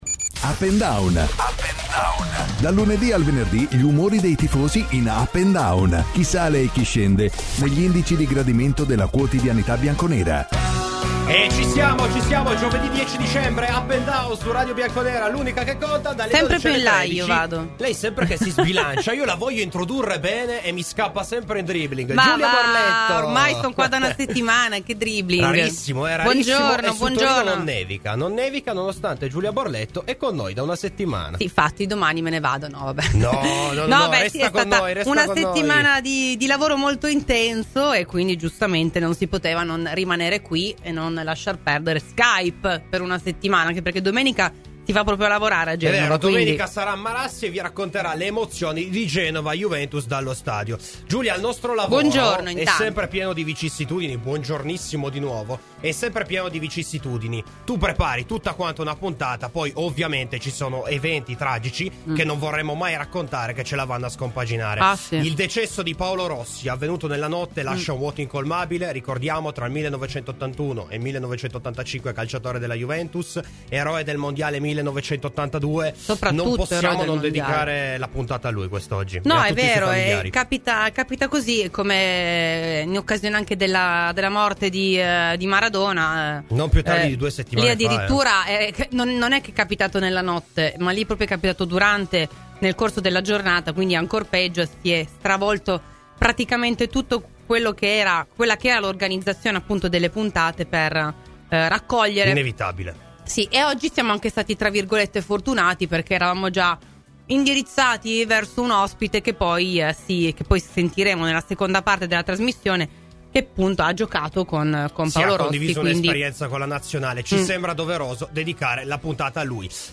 Clicca sul podcast in calce per la trasmissione integrale e l’intervista completa.